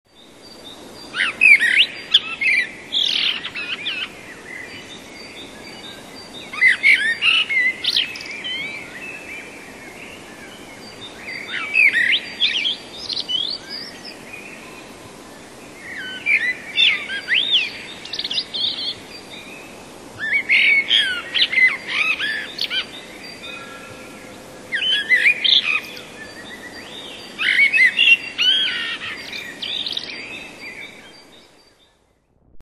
Kos - Turdus merula
Śpiew ten towarzyszy nam od wczesnej wiosny i urzeka głęboką, fletową barwą i bogactwem melodii.